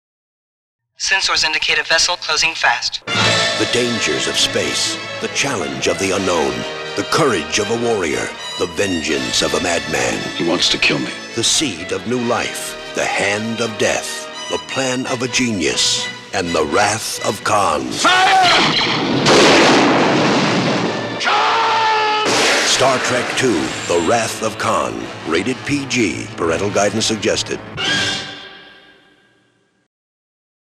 Radio Spots
The spots are very good and capture the action and mood of the movie.